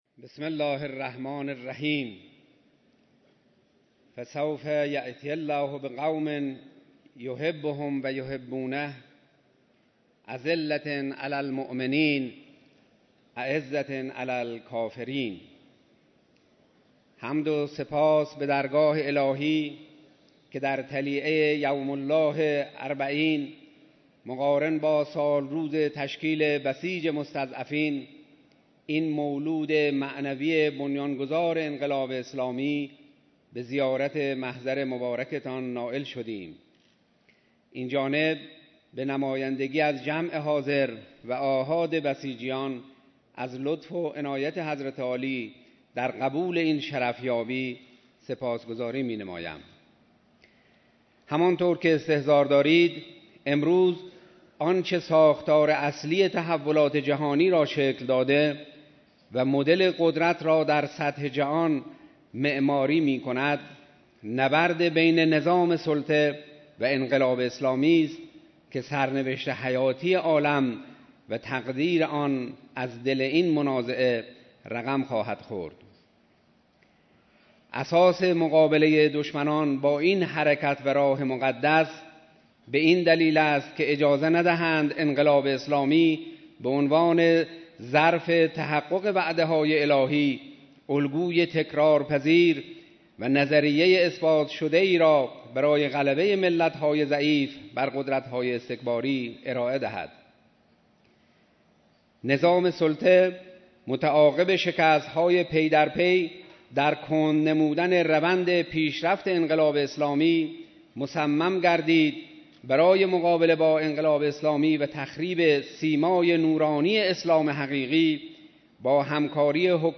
ارائه گزارش سردار سرلشکر جعفری فرمانده کل سپاه پاسداران انقلاب اسلامی